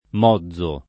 m0zzo] s. m. («pezzo della ruota; zolla») — regolare la pn. aperta dell’-o- e sonora della -z-, in un sost. che continua con significati assai variati (al pari dell’allòtropo moggio) il lat. modius con -o- breve; e così attestata da fonti scritte fin dal ’600 e registrata nei dizionari — preval. oggi però nella stessa Tosc. e più nettam. nelle regioni vicine una tendenza alla pn. con -o- chiuso e -z- sorda, dovuta a una minor frequenza della voce nell’uso quotidiano e all’attraz. delle tre voci omografe (mozzo agg., mozzo s. m., mozzo del v. mozzare), tutte più conosciute e più spesso usate